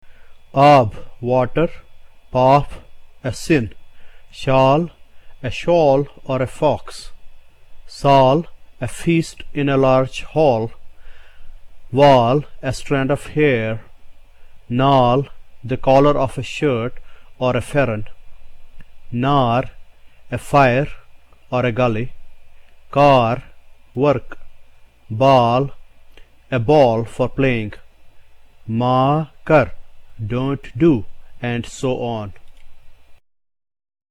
Clearly the vowel shown above stands for the sound of the letter A in the English word SCARE. In Kashmiri, the following words contain this sound: